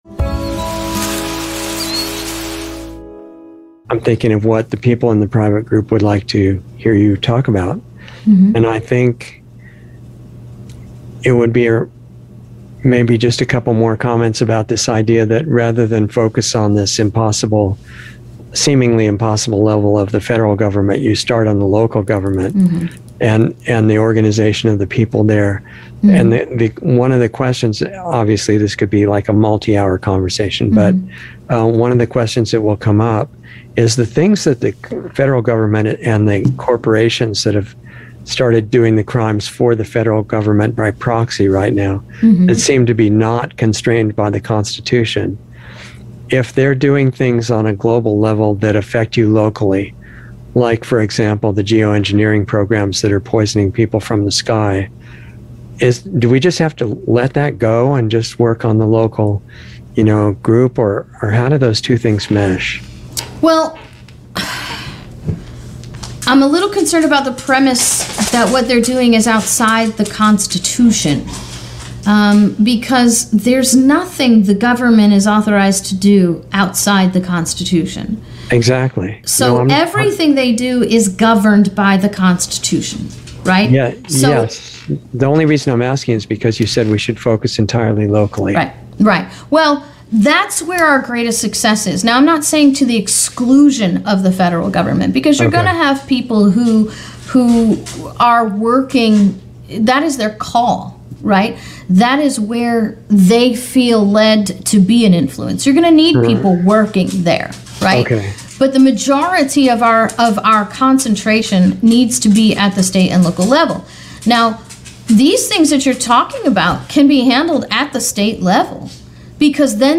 Insider Interview 11/15/21